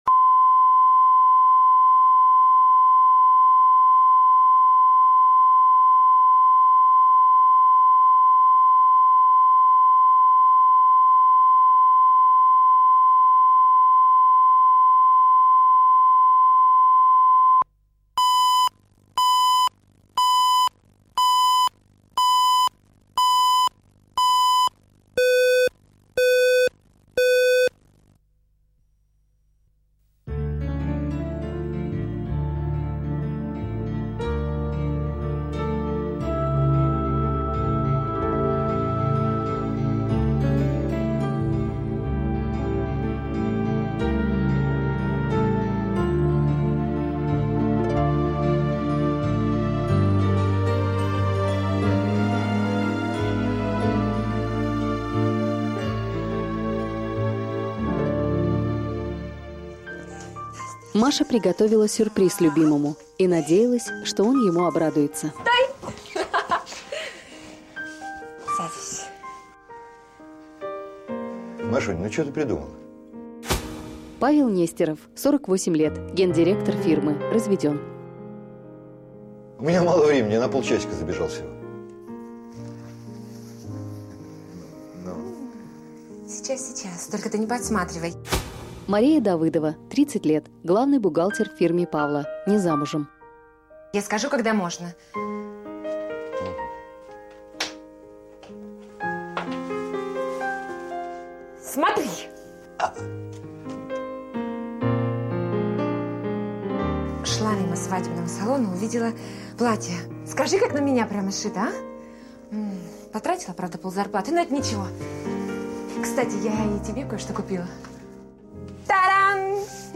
Аудиокнига Свадебное платье | Библиотека аудиокниг
Прослушать и бесплатно скачать фрагмент аудиокниги